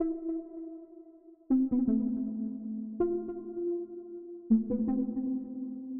标签： 150 bpm Trap Loops Bells Loops 1.01 MB wav Key : Unknown
声道立体声